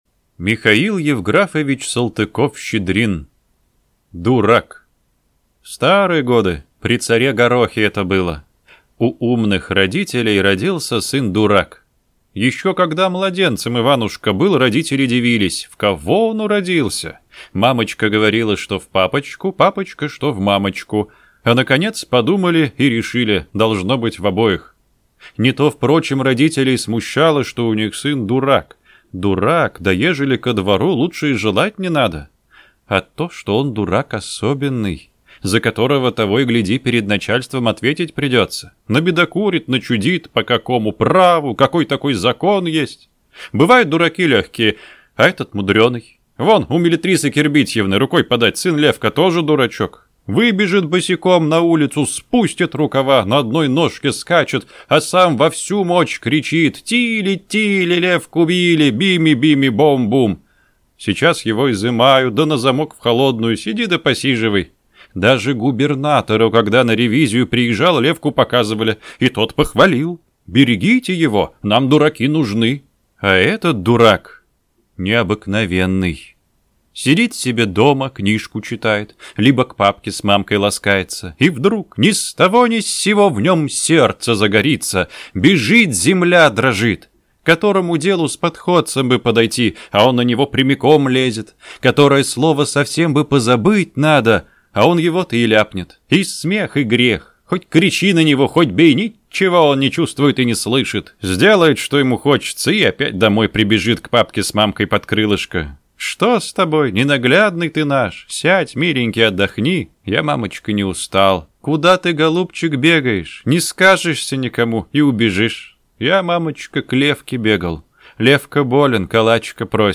Аудиокнига Дурак | Библиотека аудиокниг
Прослушать и бесплатно скачать фрагмент аудиокниги